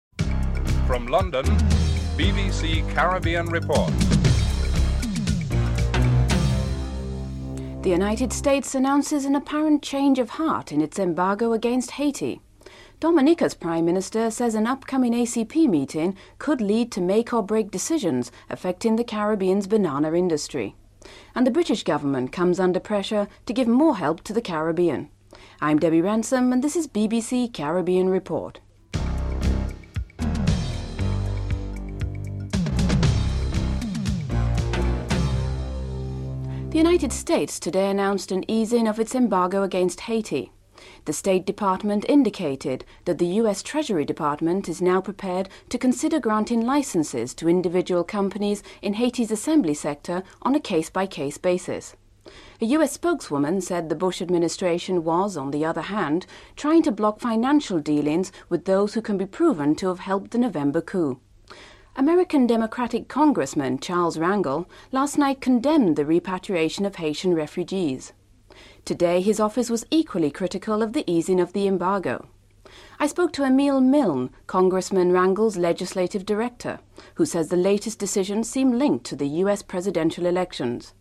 Dominica's Prime Minister, Dame Eugenia Charles re-confirms her decision to attend the ACP/EC General Assembly in Santo Domingo, rather than the Caricom leaders intercessional meeting in Jamaica; she is interviewed on what she hopes to achieve in Santo-Domingo.
1. Headlines (00:00-00:35)